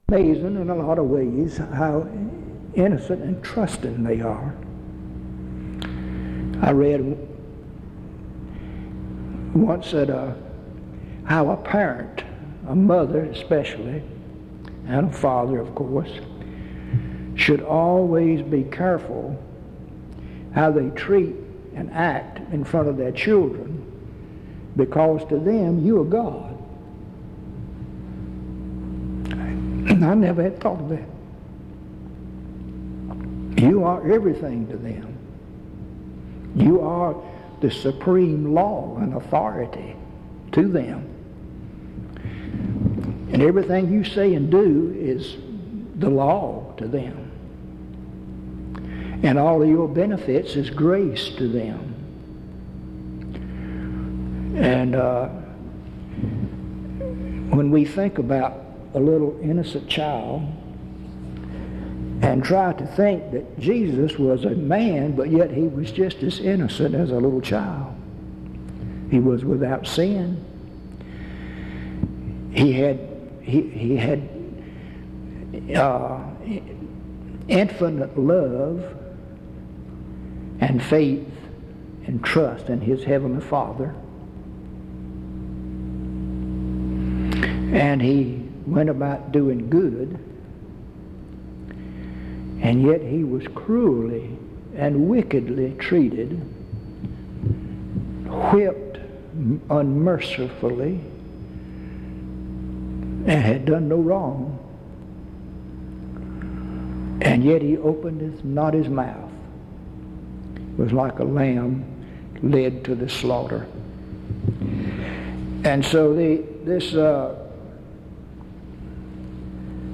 Hebrews 1:1-3; A small portion of this recording seems to be missing.
Hebrews Primitive Baptists Language English Identifier PBHLA-ACC.001_066-A-01/PBHLA-ACC.001_066-B-01 Date created 2005-04-10 Location Reidsville (N.C.) Rockingham County (N.C.)